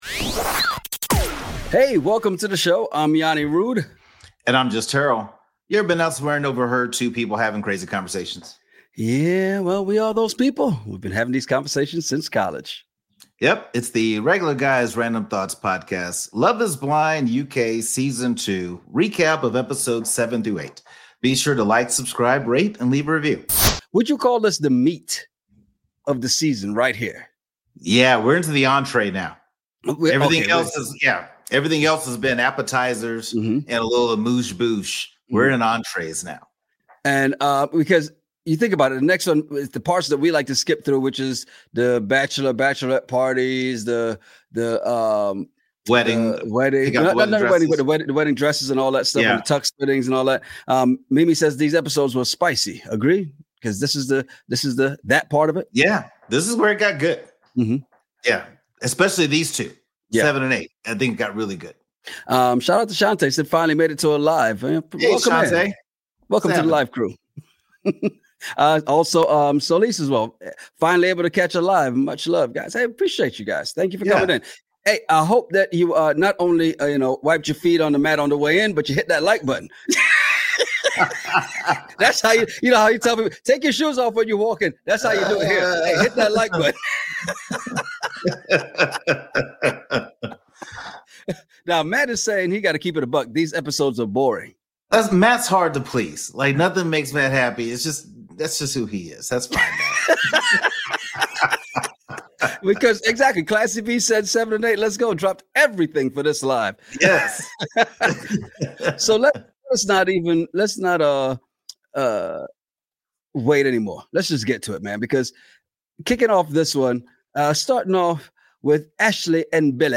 Play episode August 24 1h 11m Bookmarks Episode Description The Pod Squad meet up has a few heads turning and spinning and left repercussions days after. Ever been somewhere and overheard two guys having a crazy conversation over random topics? Well we are those guys and we have been having these conversations since college.